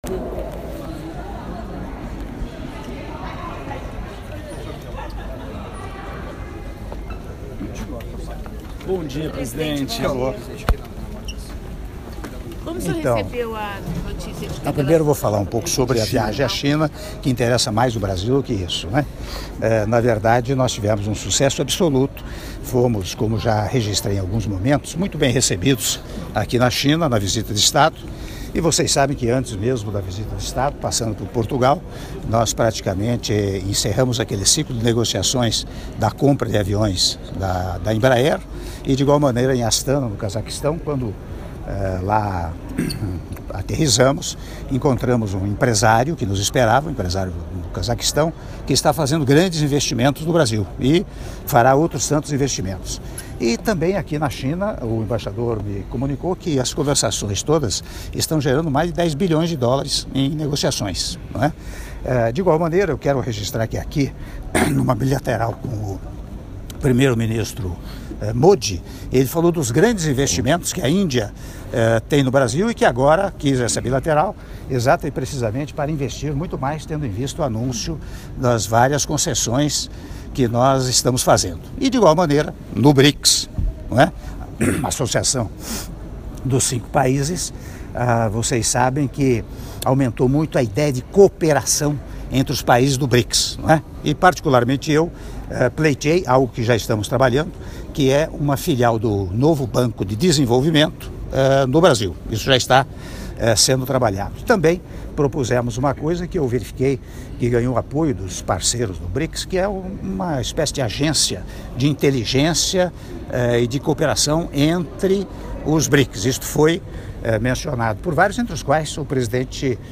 Áudio da Entrevista coletiva concedida pelo Presidente da República, Michel Temer, na saída do Hotel - Xiamen/China (03min18s)